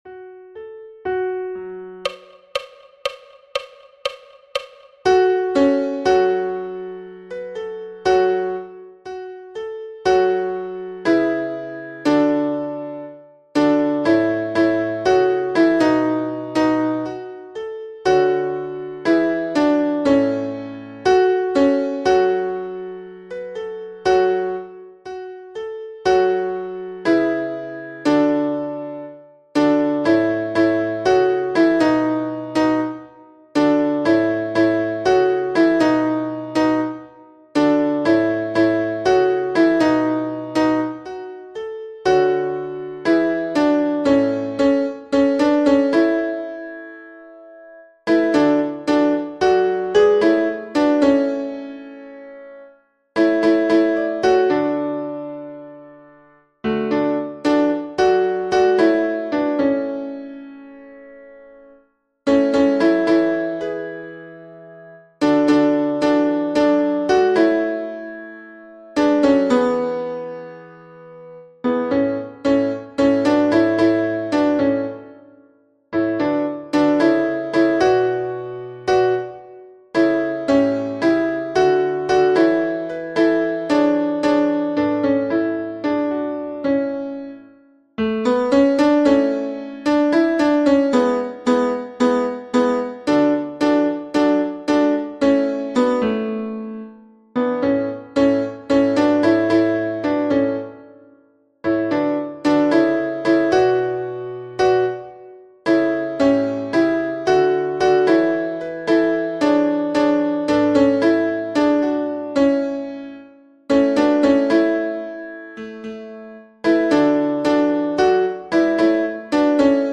storm-tenor.mp3